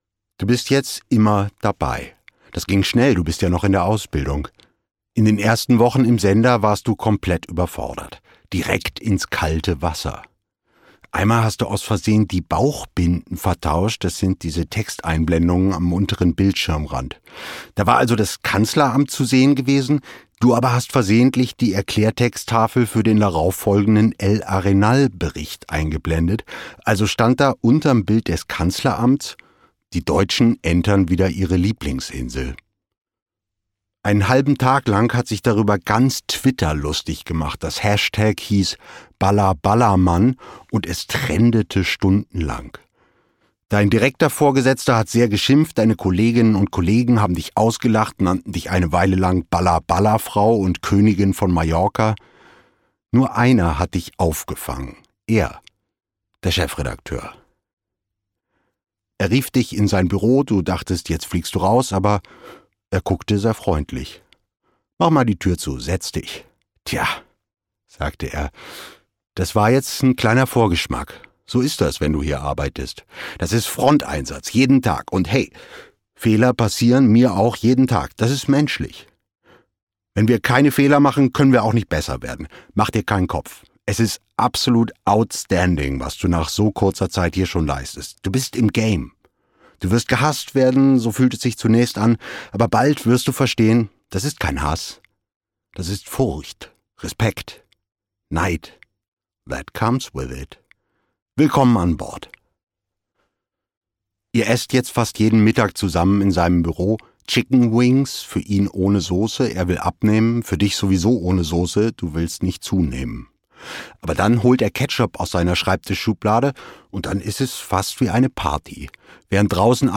Noch wach? Roman Benjamin von Stuckrad-Barre (Autor) Benjamin von Stuckrad-Barre (Sprecher) Audio Disc 2023 | 2.